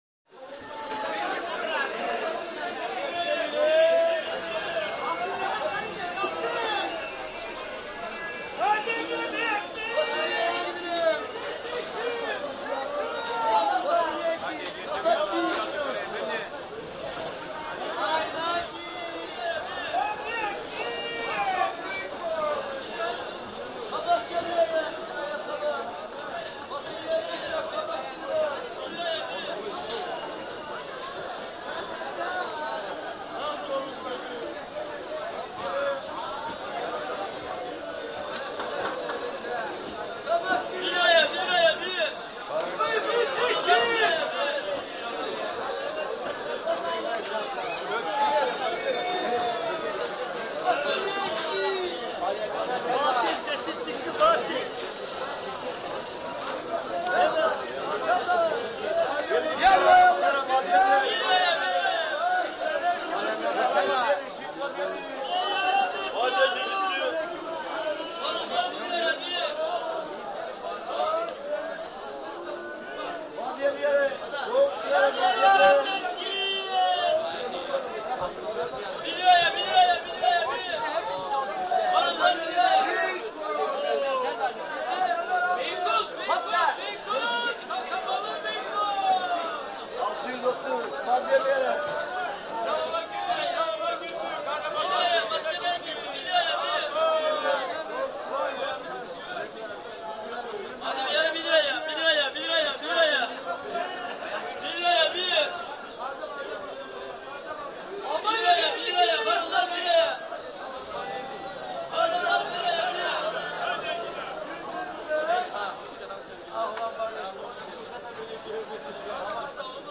Market; Arabic; Arab Street Market Atmosphere. Shouts From Traders, Background Music In Parts And Crowd Chatter.